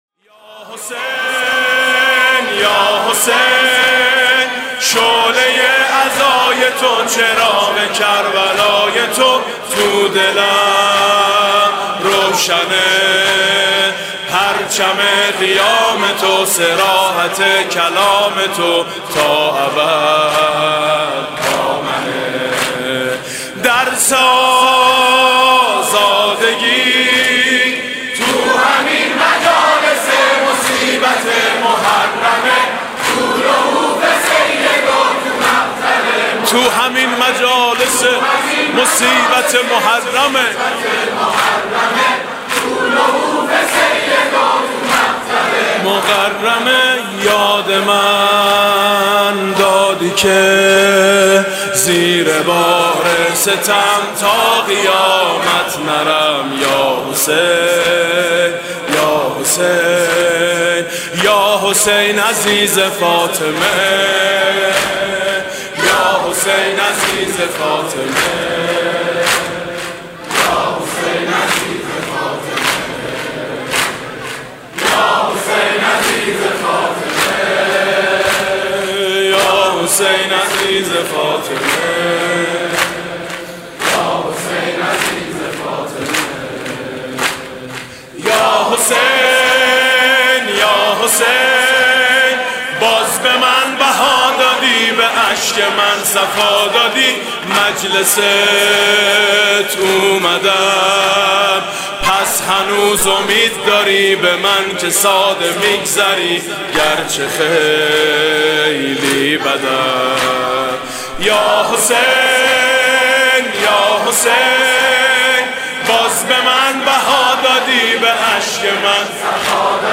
شور پایانی